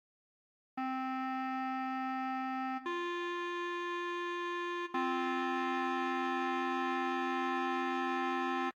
Augmented third on C Play.
In classical music from Western culture, an augmented third (
Play) is an interval of five semitones.